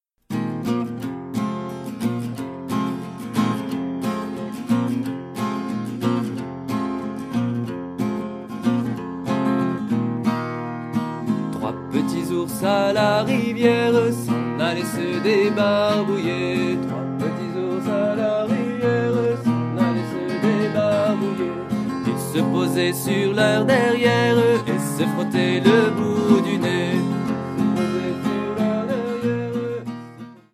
Chanson pour le cycle 1 très célèbre.